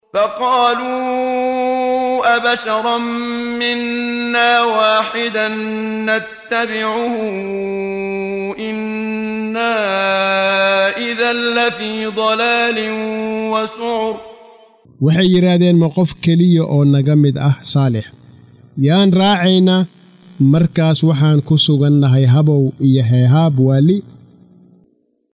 Waa Akhrin Codeed Af Soomaali ah ee Macaanida Suuradda Al-Qamar ( Dayaxa ) oo u kala Qaybsan Aayado ahaan ayna la Socoto Akhrinta Qaariga Sheekh Muxammad Siddiiq Al-Manshaawi.